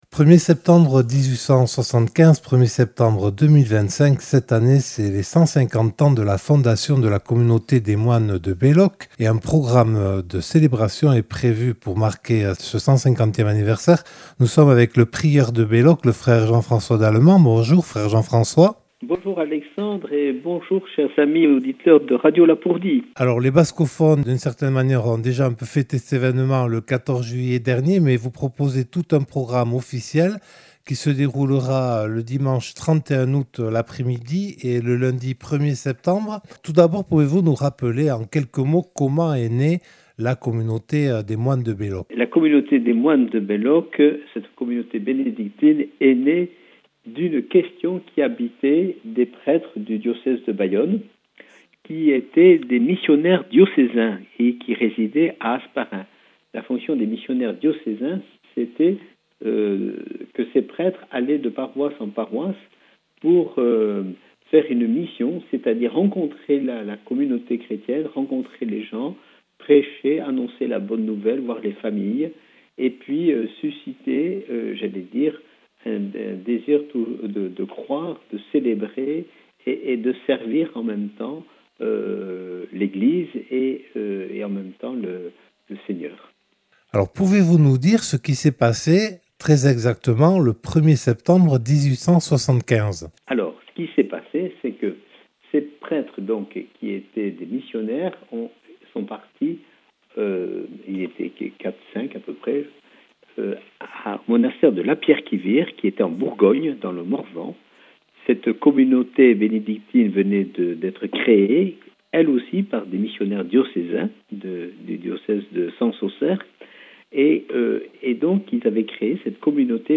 Accueil \ Emissions \ Infos \ Interviews et reportages \ ANNULÉ ET REPORTÉ - Les moines bénédictins de Belloc célèbrent les 150 ans de (...)